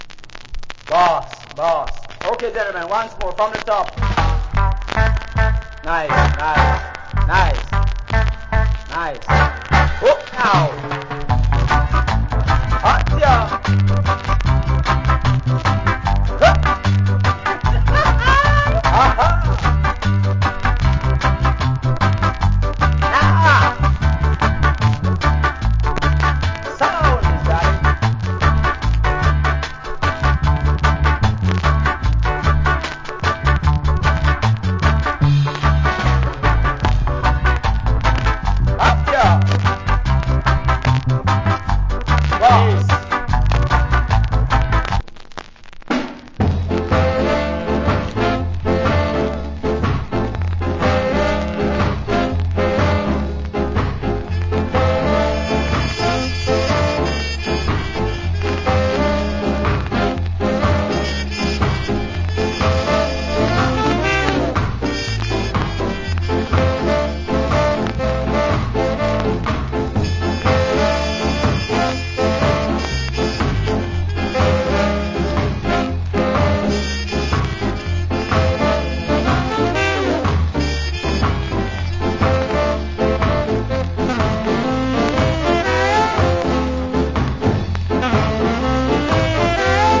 Wicked Early Reggae Inst Plus MC.